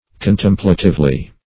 contemplatively - definition of contemplatively - synonyms, pronunciation, spelling from Free Dictionary
Search Result for " contemplatively" : The Collaborative International Dictionary of English v.0.48: Contemplatively \Con*tem"pla*tive*ly\, adv.